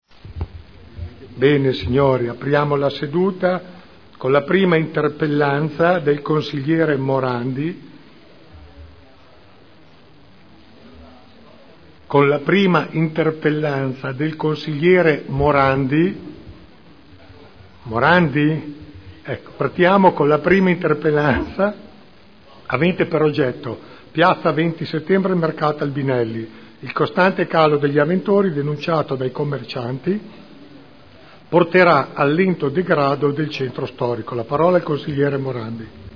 Seduta del 18 ottobre 2010 - Il Presidente Pellacani inizia la seduta dando la parola al consigliere Morandi